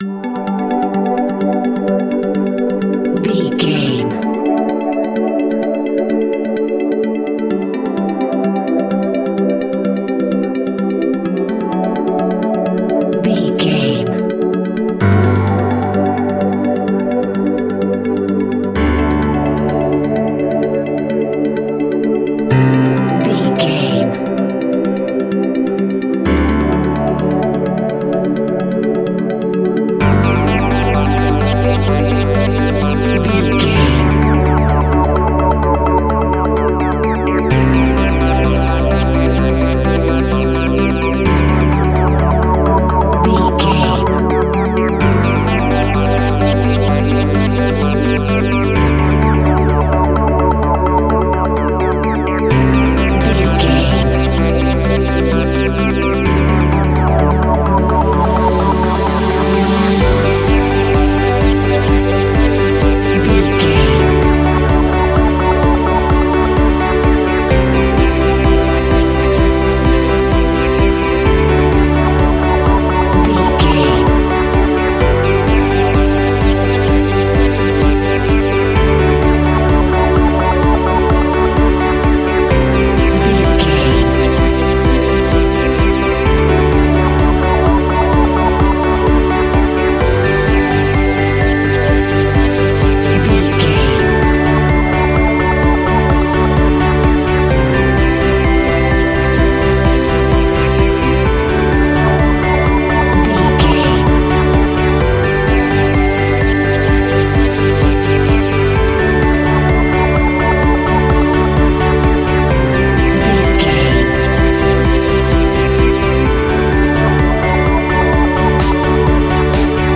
Uplifting
Ionian/Major
pop rock
indie pop
energetic
motivational
cheesy
guitars
bass
drums
piano
organ